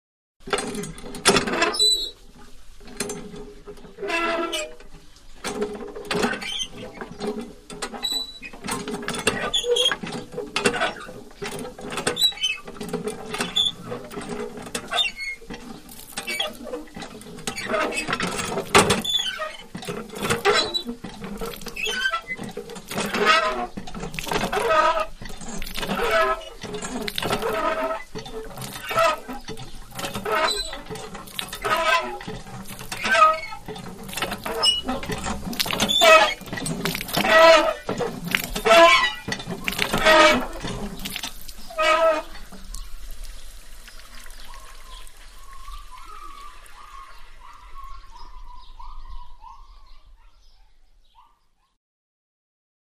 Hand Pump | Sneak On The Lot
Manual Water Pump; Water Pump Cranked, Water Starts To Flow And Splatter Into Stone Horse Trough, Then Stop - Distant Yelping Puppy After Stop On Fade